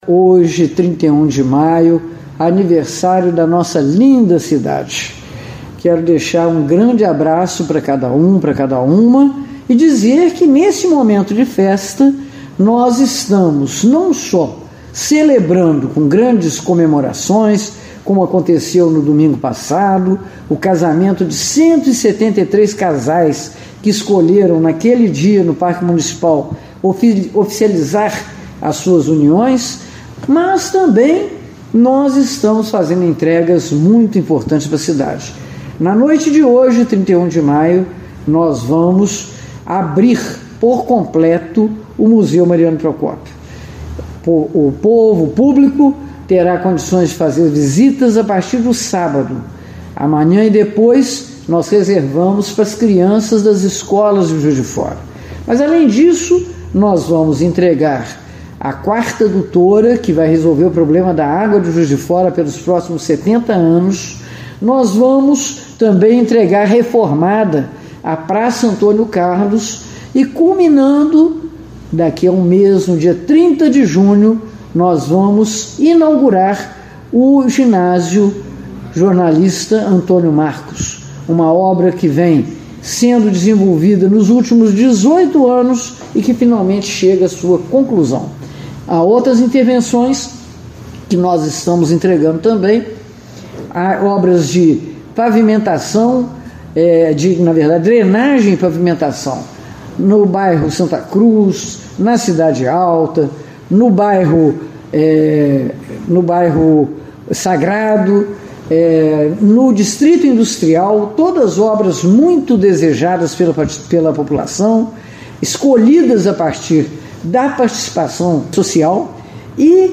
A reabertura do Museu integra a programação pelos 173 anos de Juiz de Fora, como outras ações anunciadas pela Prefeitura, como explica a prefeita Margarida Salomão.